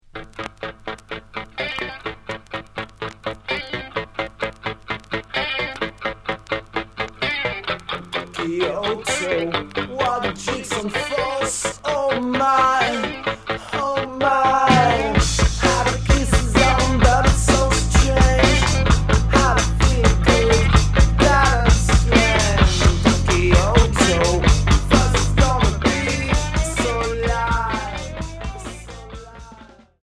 Rock
batterie